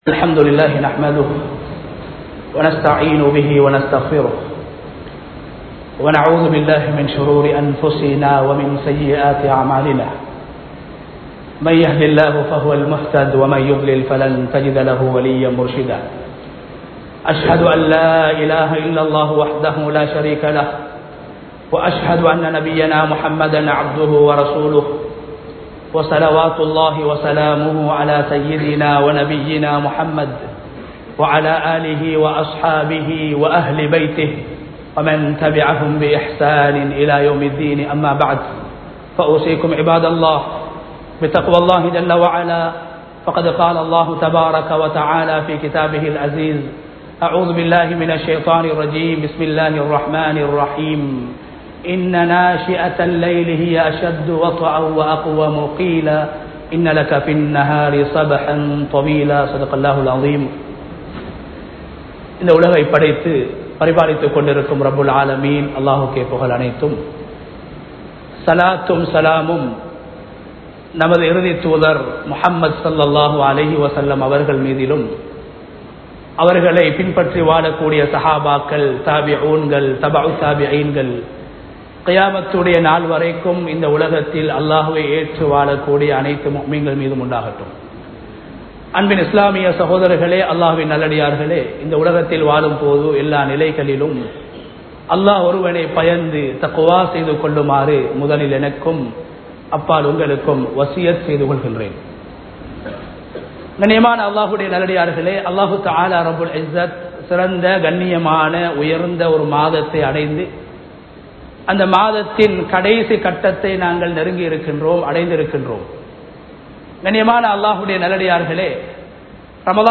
ரமழானின் இறுதி 10 | Audio Bayans | All Ceylon Muslim Youth Community | Addalaichenai
Rathmalana Jumua Masjidh 2025-03-21 Tamil Download